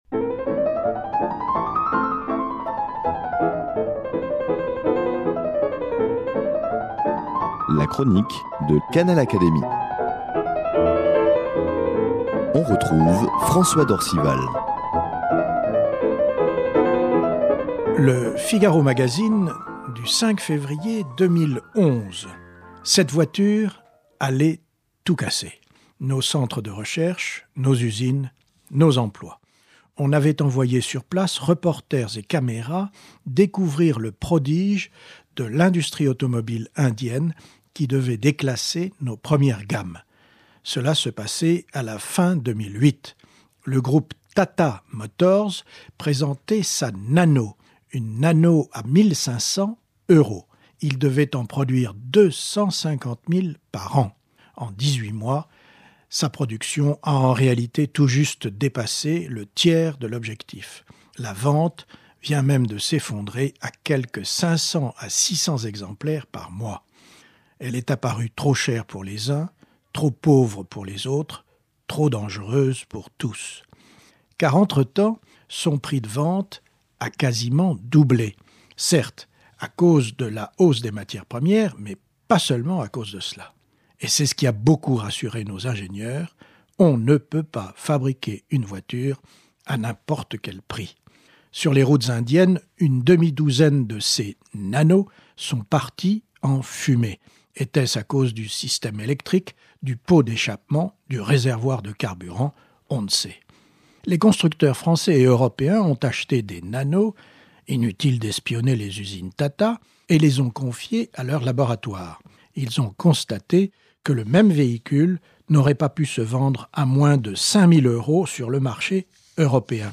L’académicien affirme que la France reste compétitive, même dans le domaine automobile où la concurrence est pourtant rude... François d’Orcival reprend ici, au micro de Canal Académie, la chronique qu’il donne, le samedi, dans Le Figaro Magazine.